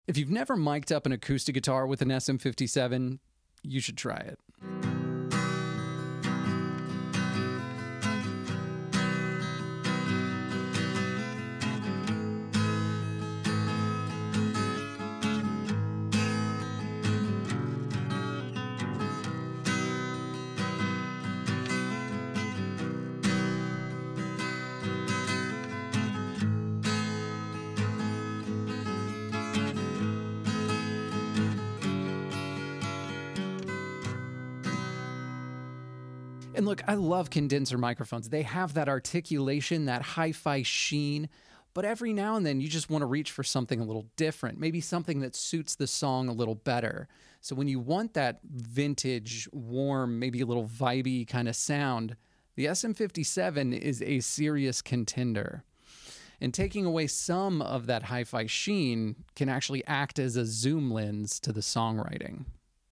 SM57 On An Acoustic Guitar.